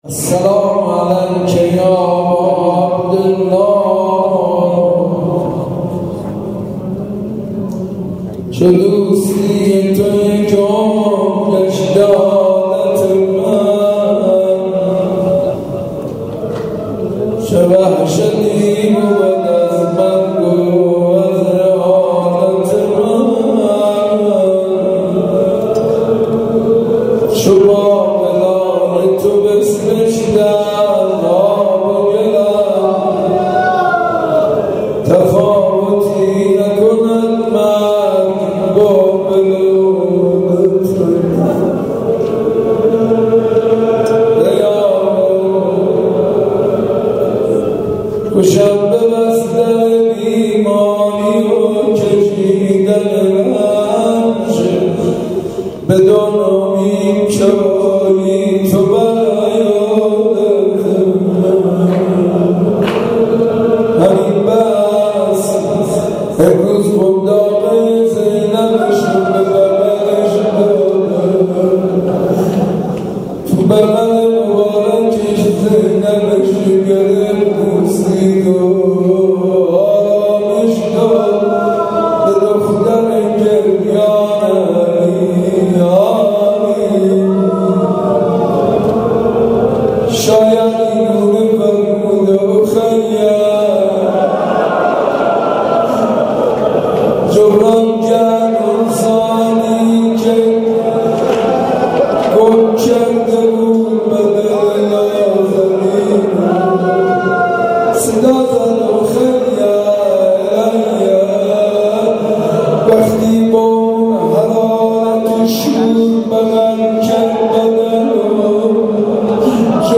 مداحی حاج منصور ارضی در اجتماع بزرگ هیئات مذهبی در مسجد ارک
پایان بخش مراسم نیز مداحی کوتاه حاج منصور ارضی بود.
شعر خوانی کوتاه حاج منصور ارضی